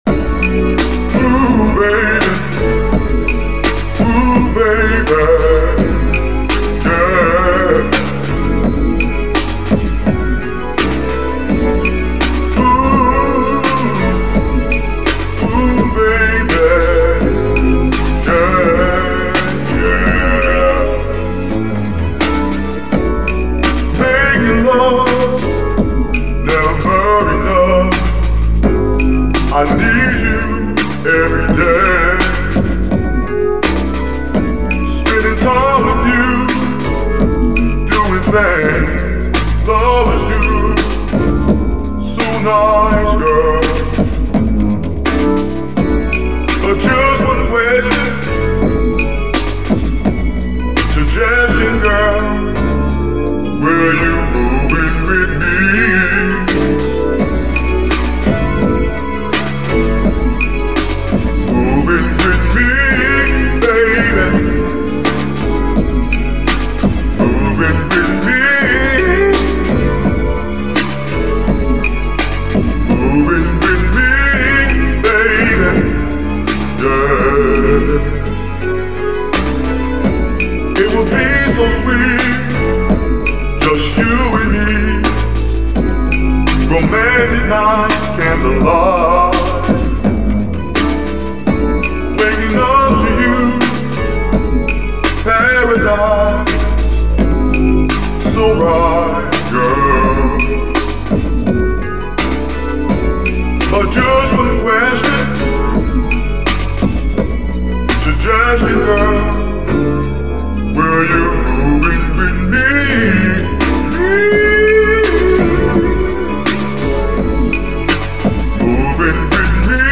ROMANCE LOVE